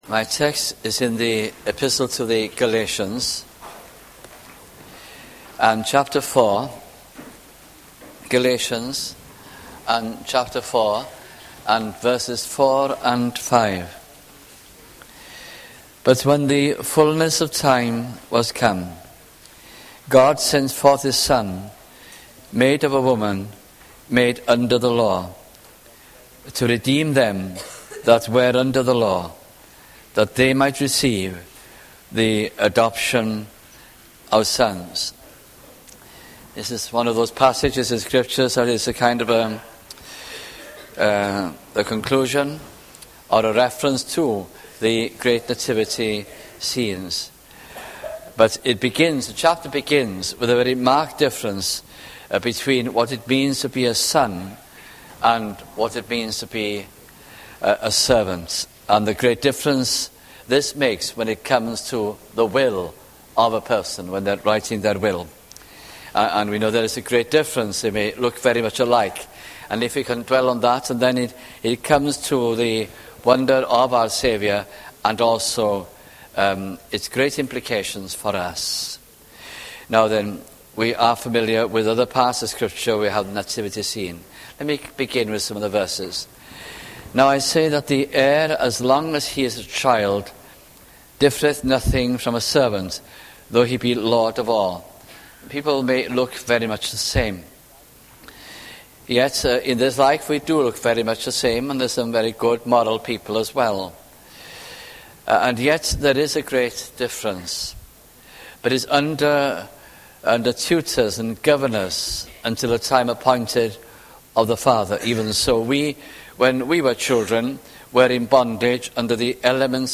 » Galatians Gospel Sermons